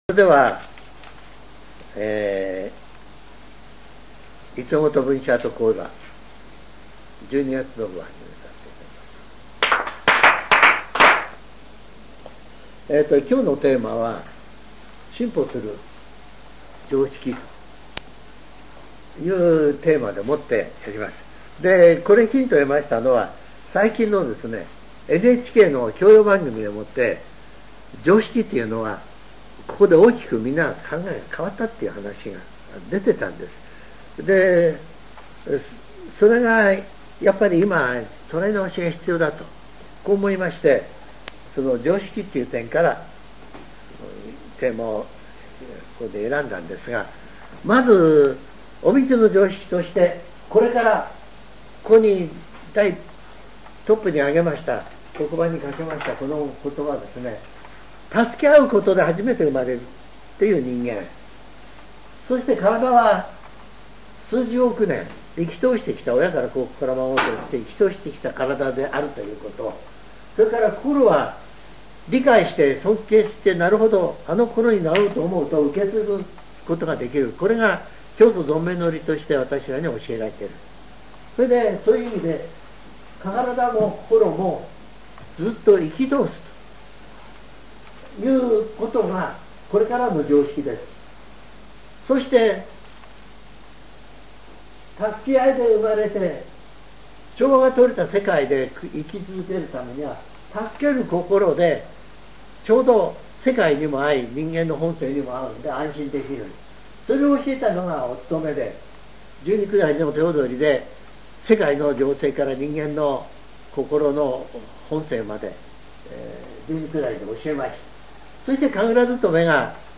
全70曲中55曲目 ジャンル: Speech